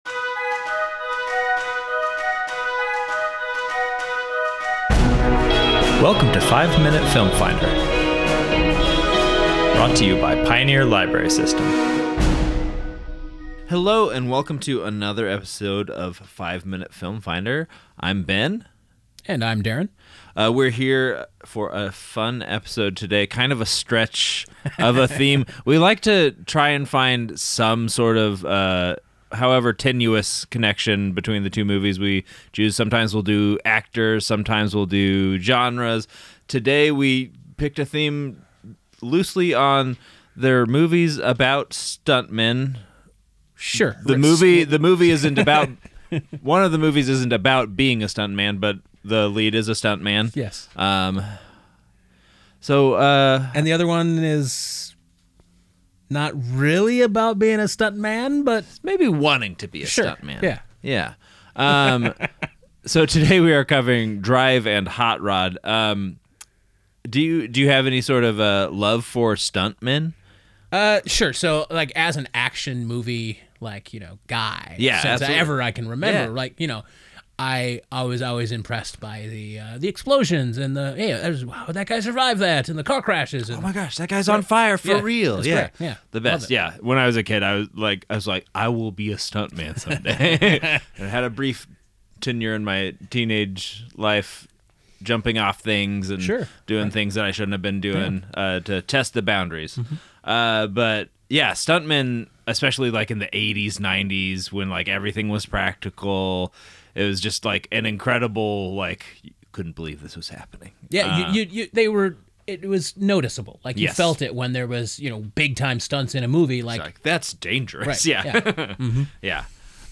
Our hosts have five minutes to inform and sell you on the movies covered in this episode.